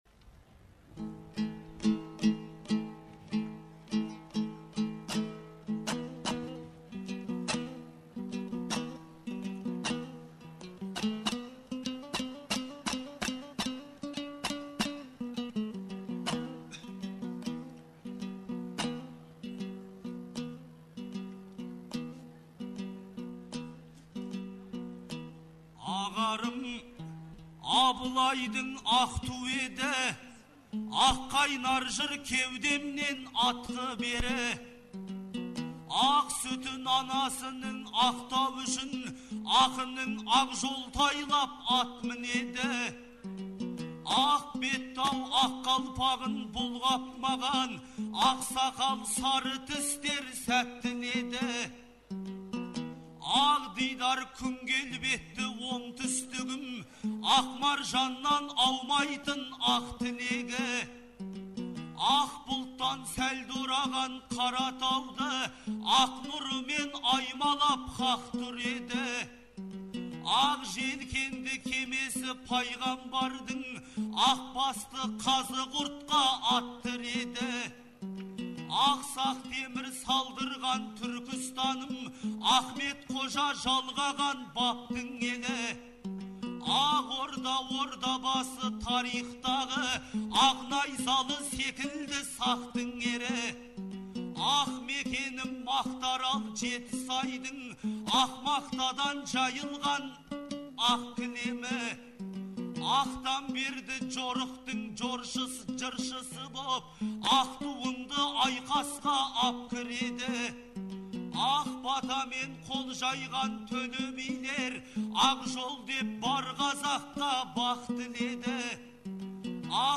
Наурыздың 15-16-сы күндері Шымкентте республикалық «Наурыз» айтысы өтті. 2004 жылдан бері тұрақты өтіп келе жатқан бұл айтыс биылғы жылы Төле бидің 350 және Абылай ханның 300 жылдықтарына арналды.